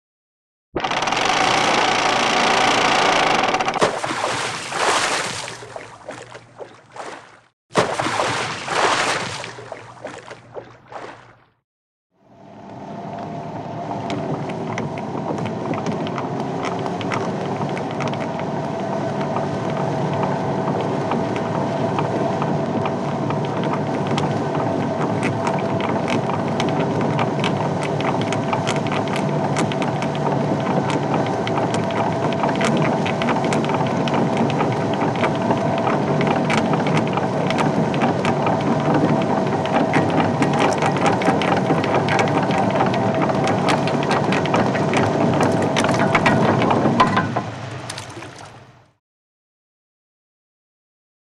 ShipAnchorLowering 3D033401
Ship; Anchor; Lowering Anchor And Raising Anchor.